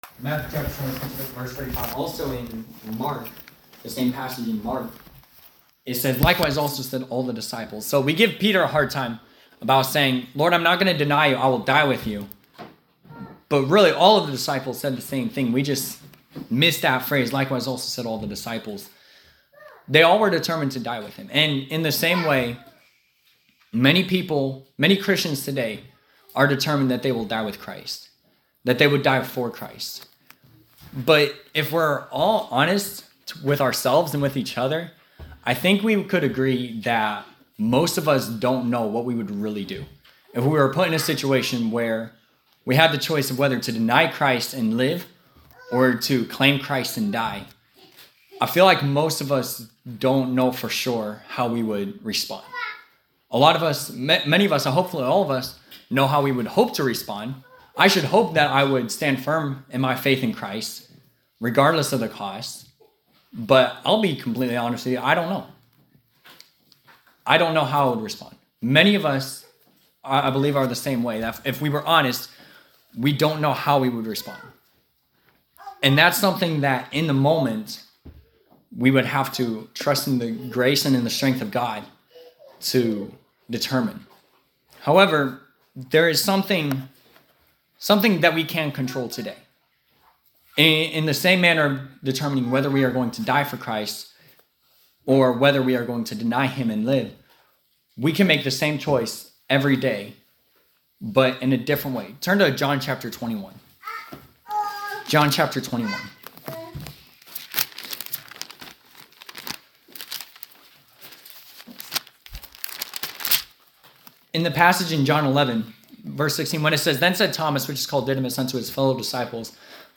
New Year’s Eve Service
Preacher: Multiple Preachers